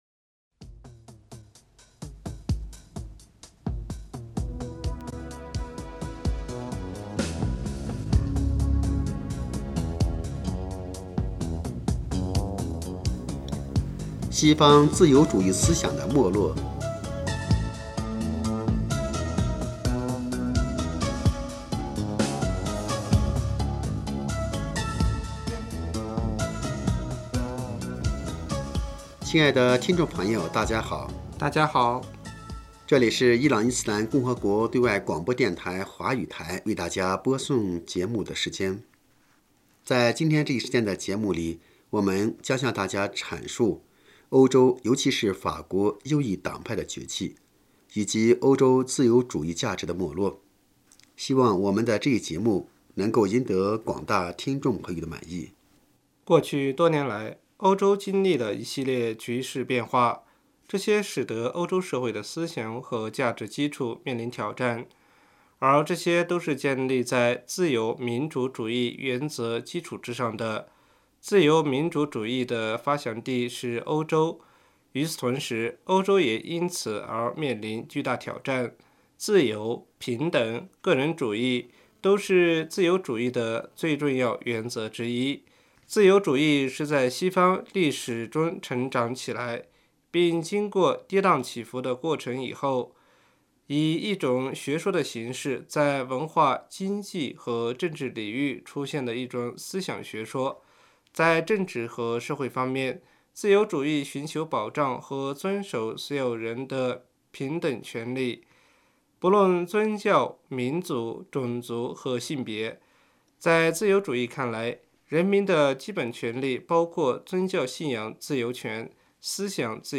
这里是伊朗伊斯兰共和国对外广播电台华语台为大家播送节目的时间。在今天这一时间的节目里，我们将向大家阐述欧洲尤其是法国右翼党派的崛起，以及欧洲自由主义价值的没落。